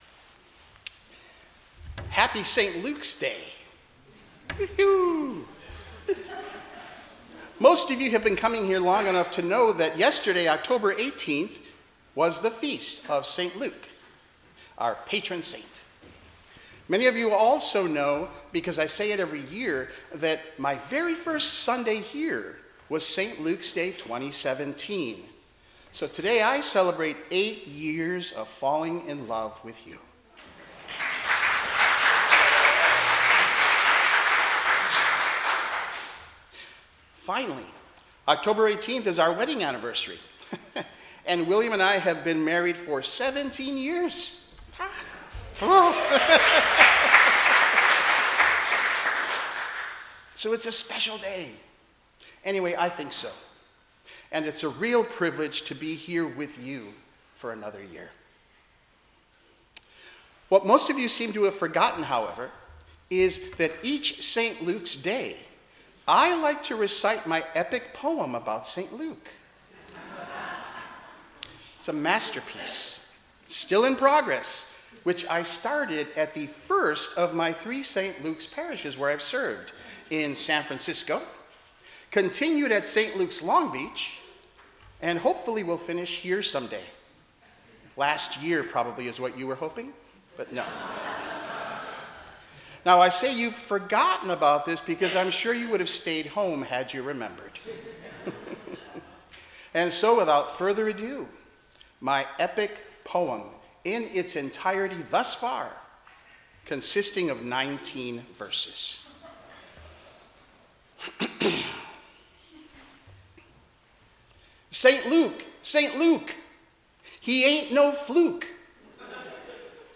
Service Type: 10:00 am Service